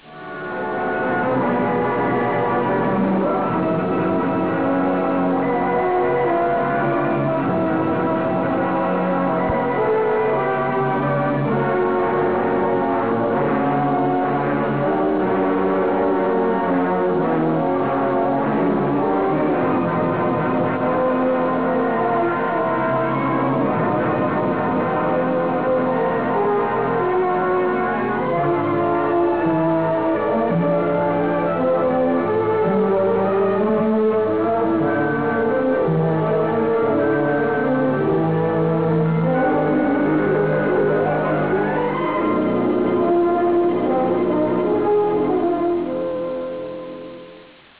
Musica
Track Music